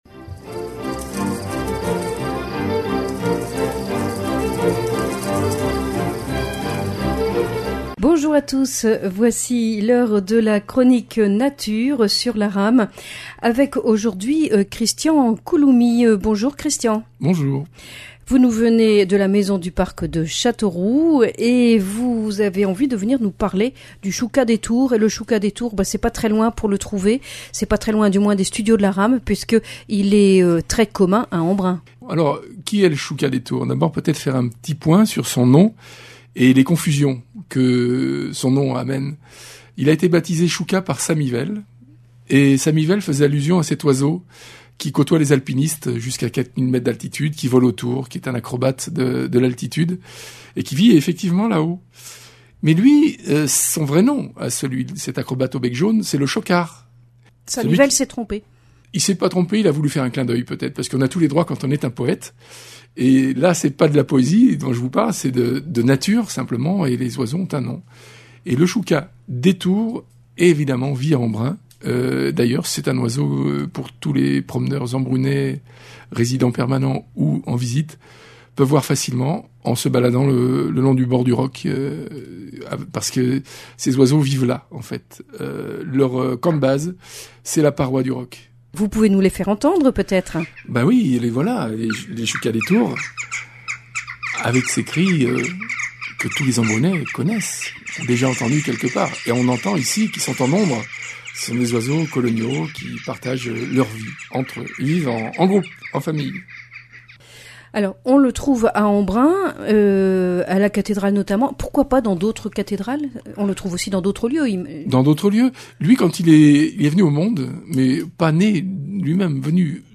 Chronique nature